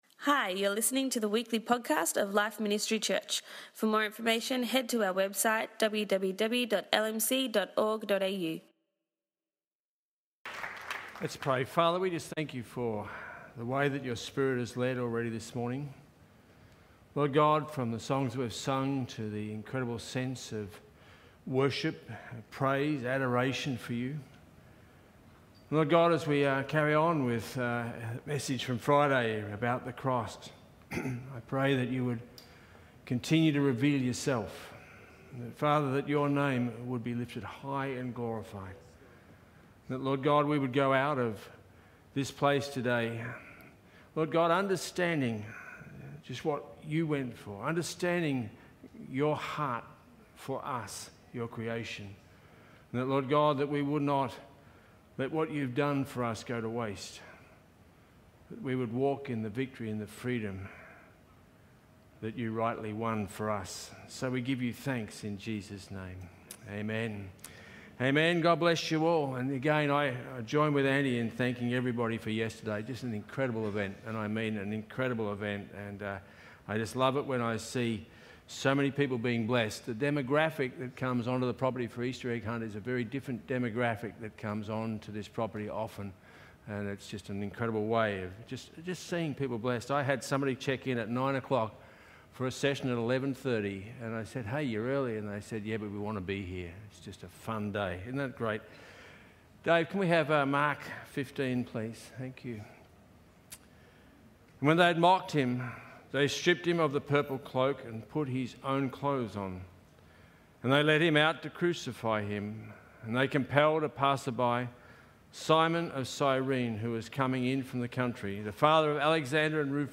For our Resurrection Sunday service